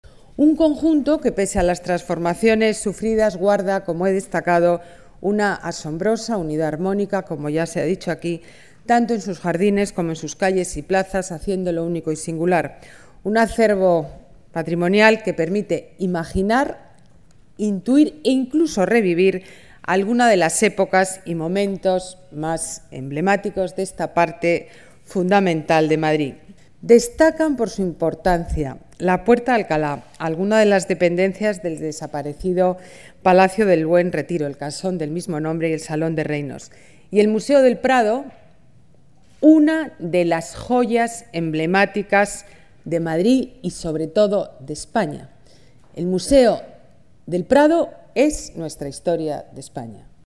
Declaraciones alcaldesa Ana Botella: Propuesta patrimonio Unesco Retiro y Prado Imágenes del proyecto del Retiro y Prado para declaración Patrimonio Mundial de la Unesco Vídeo Acto Patrimonio UNESCO Retiro